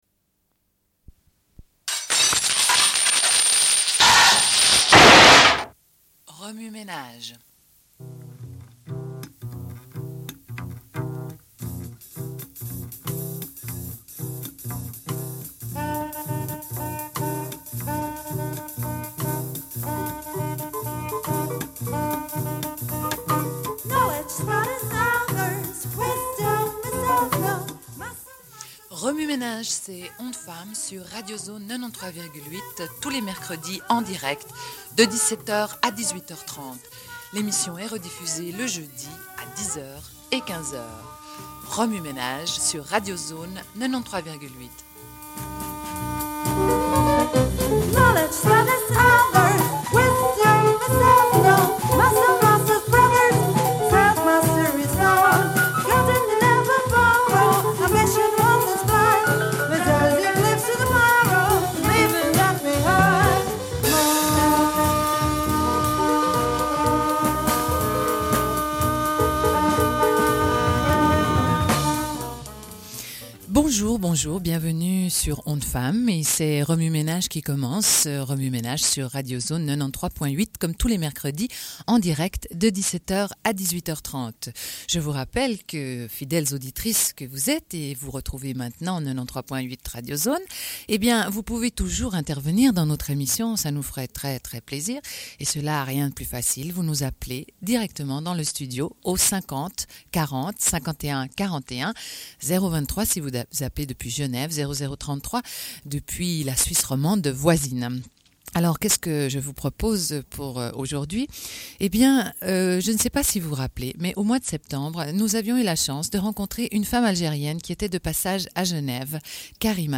Une cassette audio, face A00:31:30
Sommaire de l'émission : entretien avec Kalida Messaoudi, présidente de l'Association indépendante pour le triomphe des droits des femmes (AITDF) en Algérie.